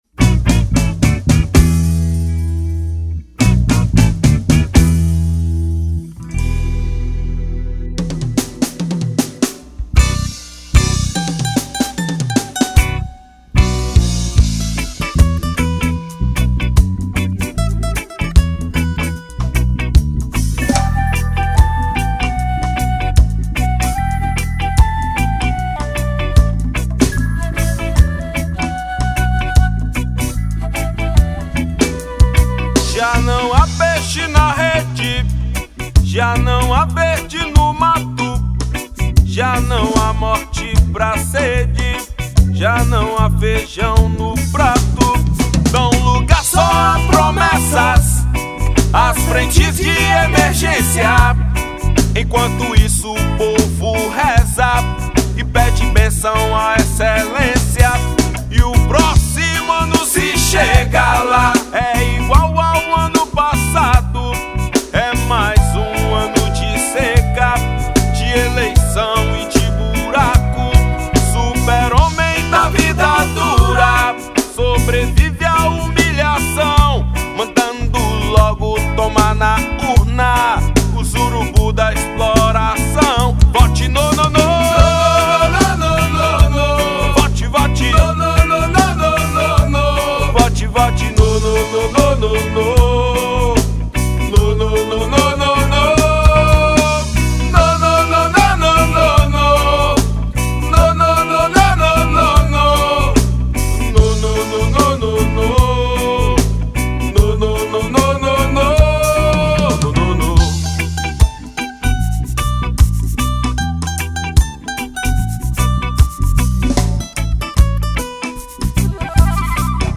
2518   03:36:00   Faixa: 10    Rock Nacional